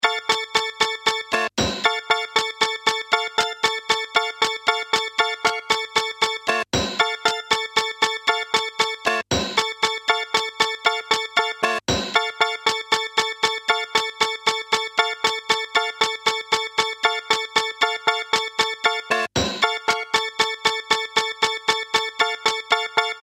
1 LFOtrigger active
It also happens that the shorting sometimes alters the timbre.